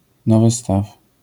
Ficheiro de áudio de pronúncia.